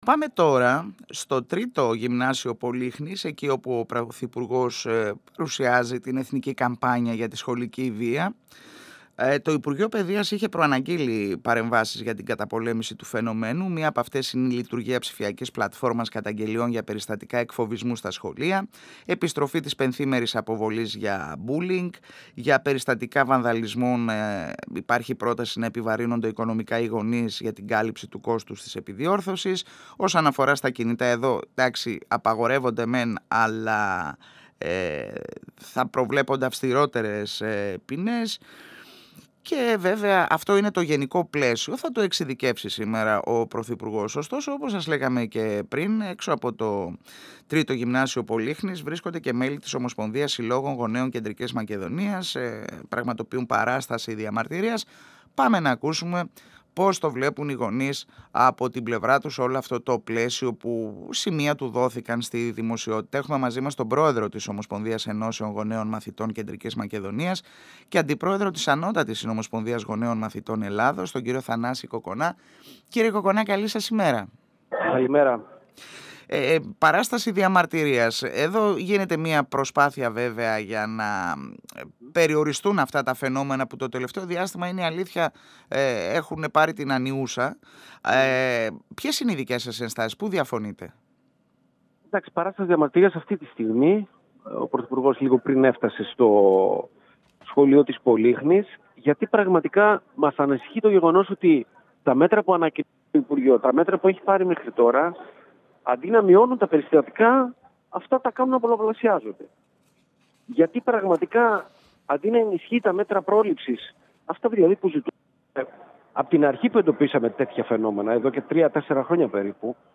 Εδω και τωρα Συνεντεύξεις